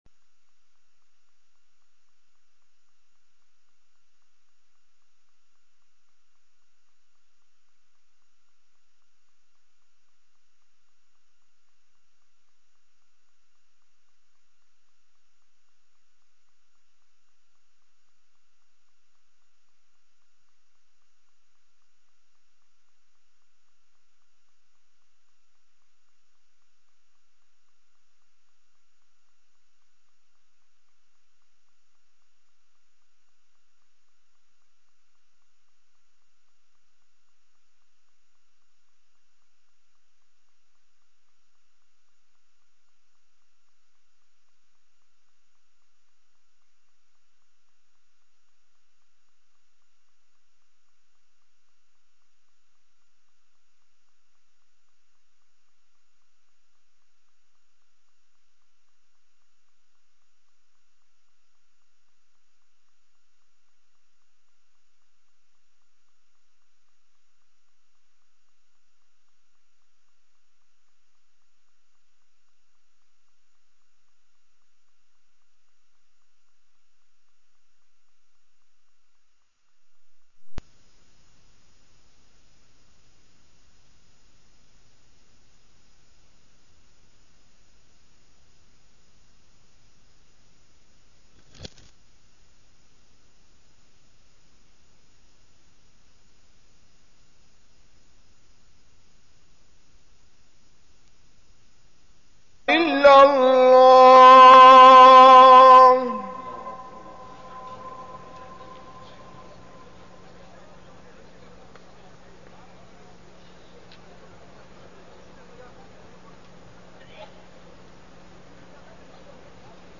تاريخ النشر ١٠ رجب ١٤١٩ هـ المكان: المسجد الحرام الشيخ: عمر السبيل عمر السبيل الوسطية و الإعتدال